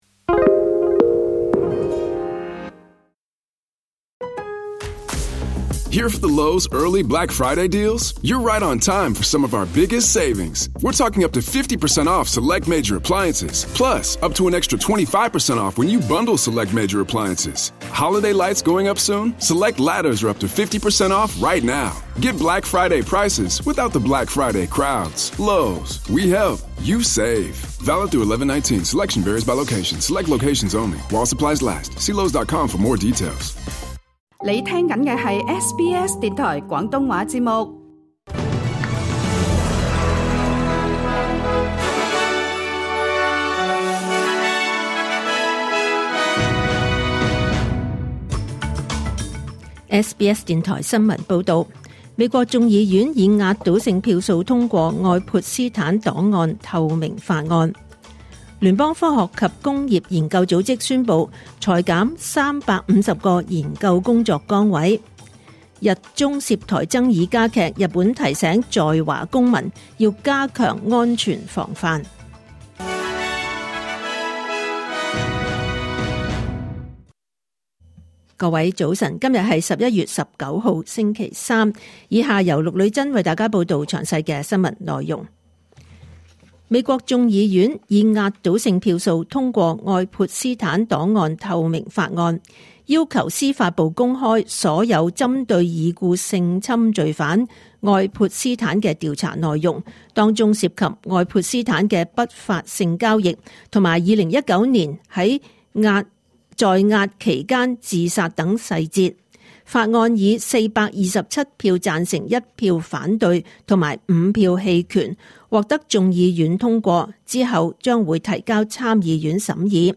2025年11月19日SBS廣東話節目九點半新聞報道。